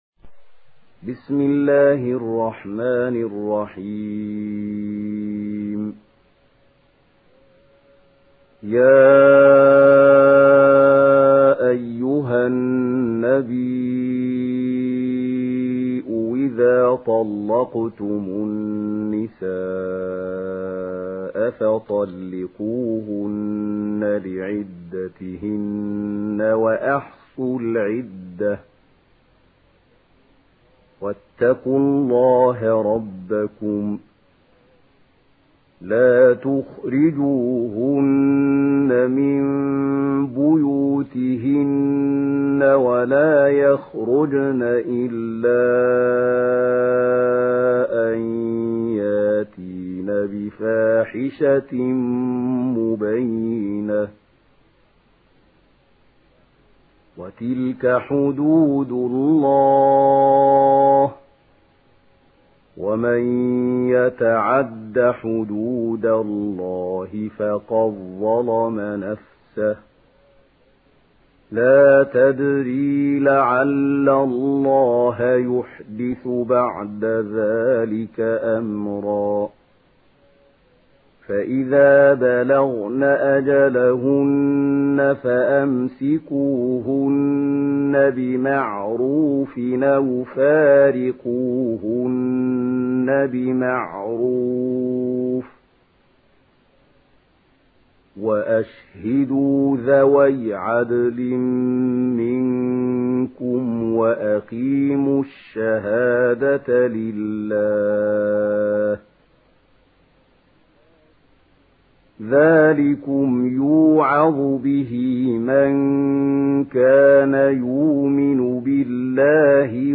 سورة الطلاق MP3 بصوت محمود خليل الحصري برواية ورش
مرتل ورش عن نافع